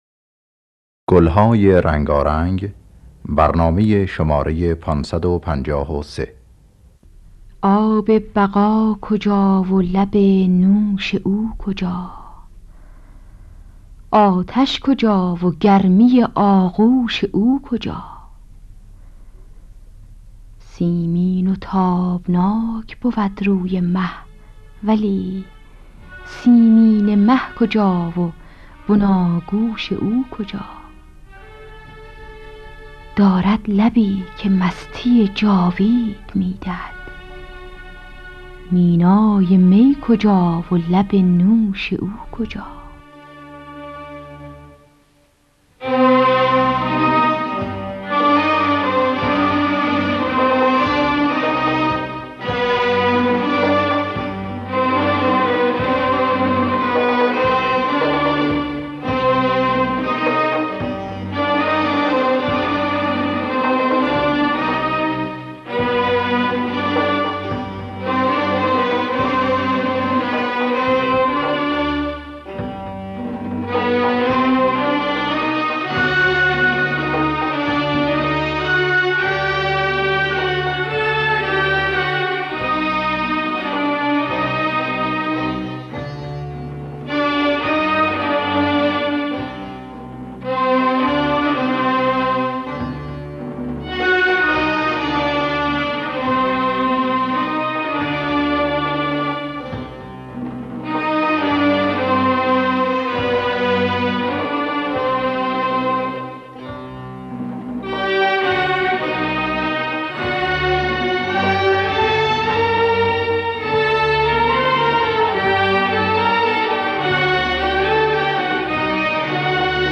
در دستگاه همایون